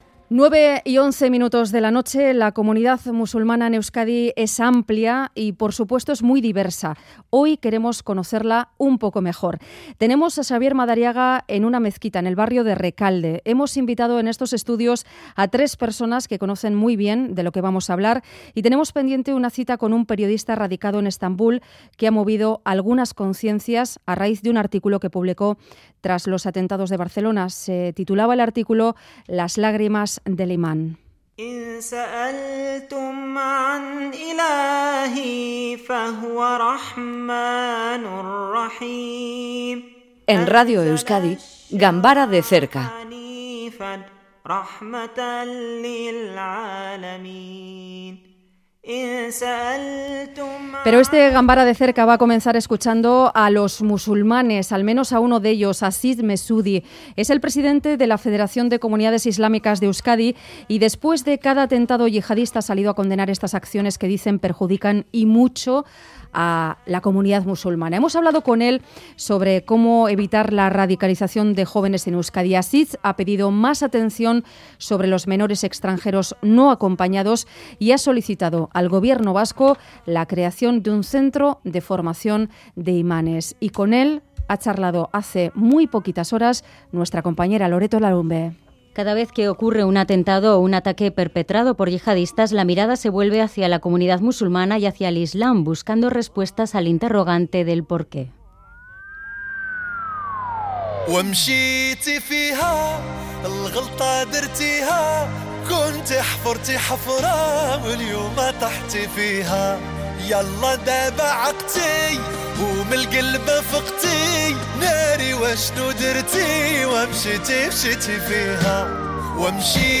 Conectamos con una mezquita en directo.